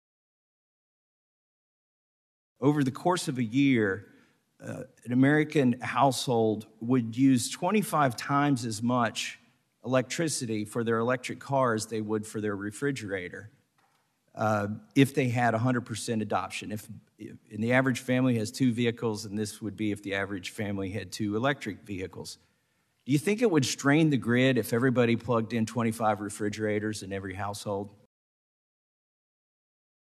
At a July 19 congressional hearing Republican Rep. Thomas Massie of Kentucky took Transportation Secretary Pete Buttigieg to school on the feasibility of Biden’s executive order.
rep-massie-grills-buttigieg.mp3